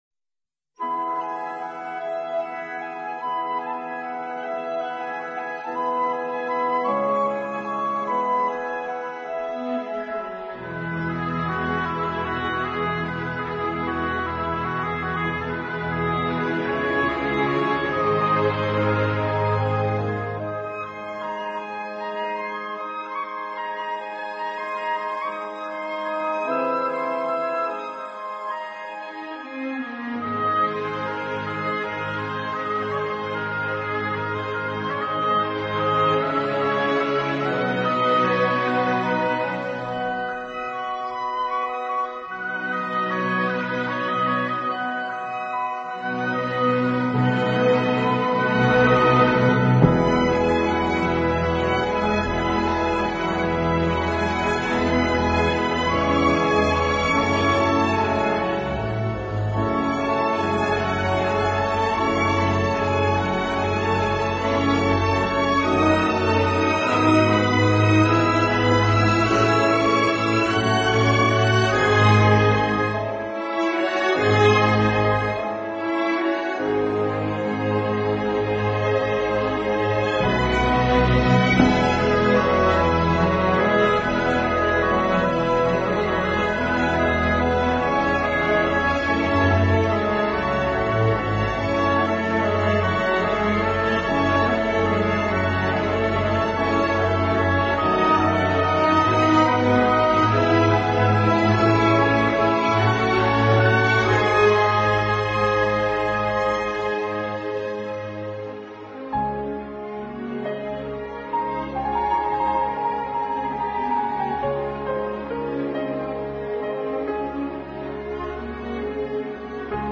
【轻音乐专辑】
这个在最初创建时的室内演奏乐队，后来被加进很多节奏性元素以改善18世纪的